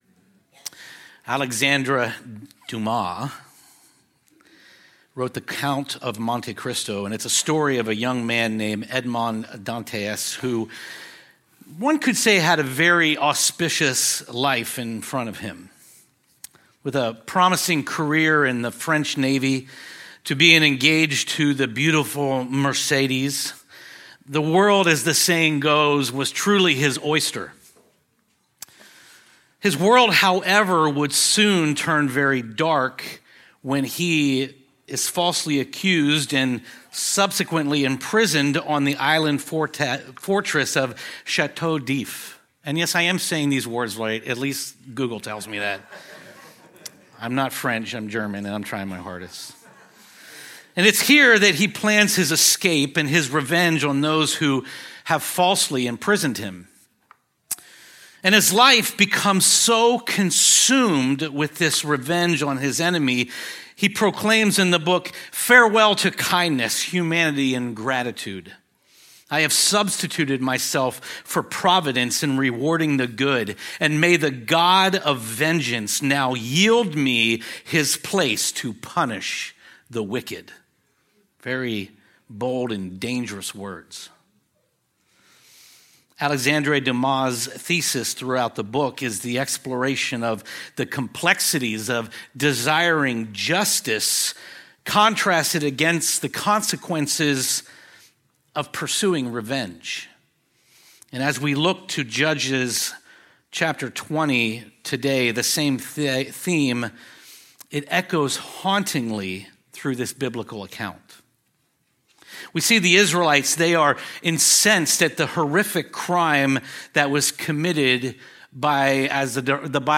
A sermon on Judges 20:29-48